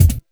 41 BD 01  -R.wav